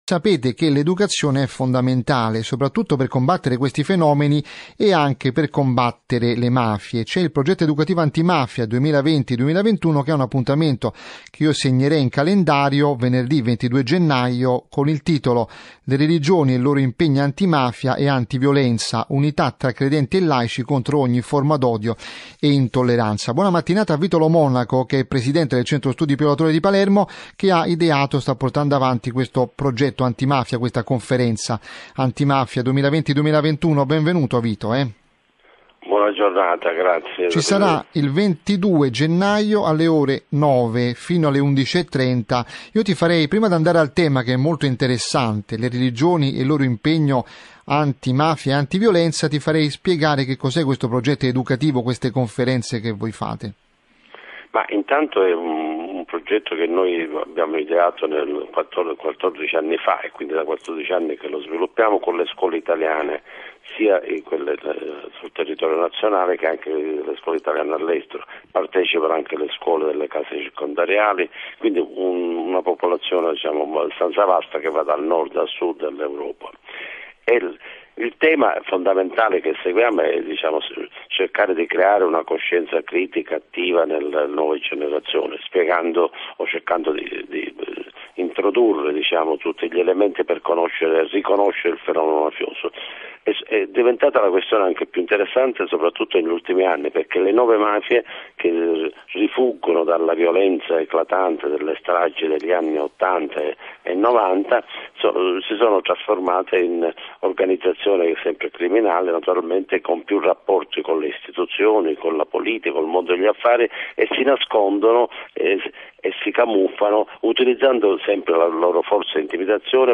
Intervista di Radio Vaticana